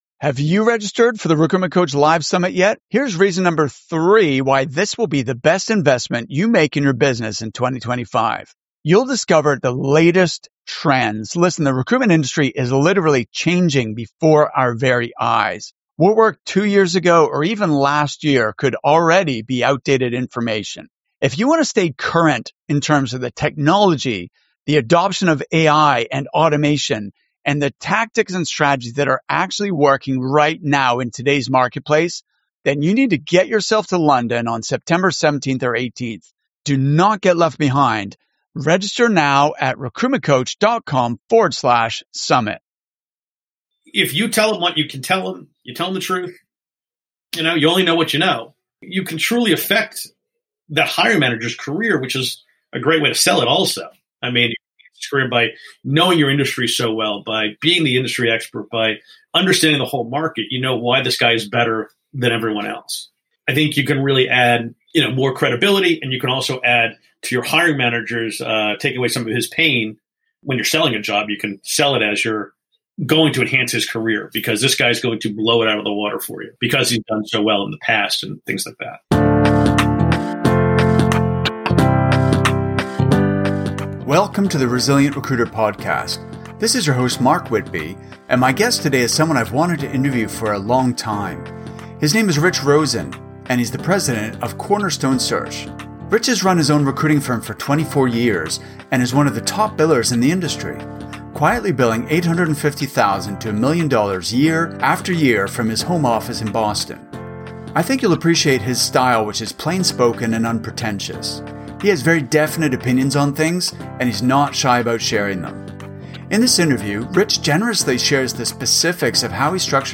This is a fun conversation full of insights from an everyday guy with a successful track record of recruiting success.